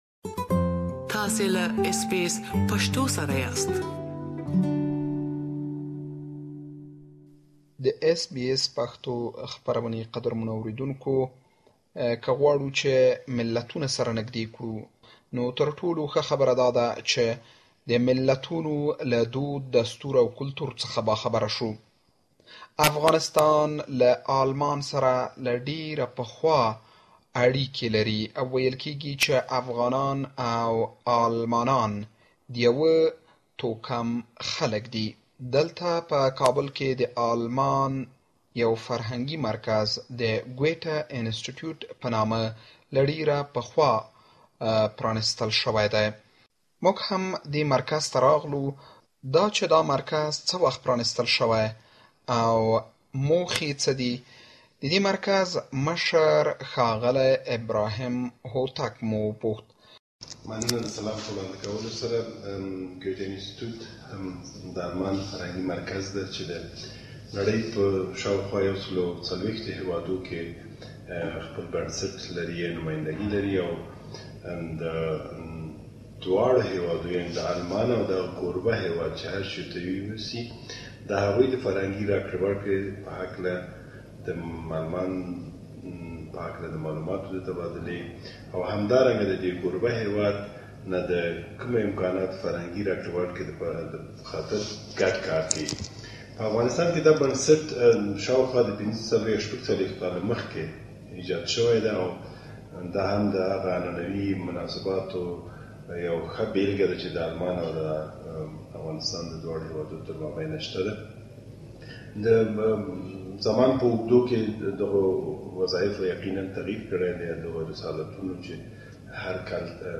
Germany has a Kabul Based institute called Goethe-Institut German which provides different kinds of services to the Afghans. We looked at this centre's operation and prepared a report that you can listen to it here.